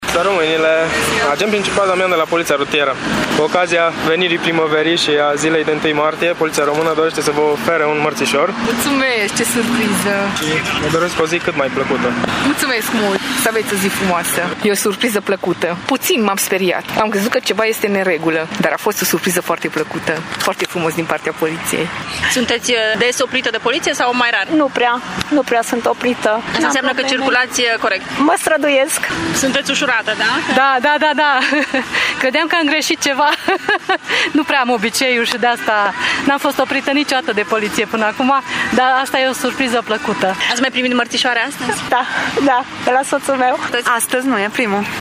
Cele mai multe dintre șoferițe s-au speriat când le-a oprit poliția în trafic, dar au răsuflat ușurate când au primit în dar mărțișorul:
stiri-1-martie-vox-soferite.mp3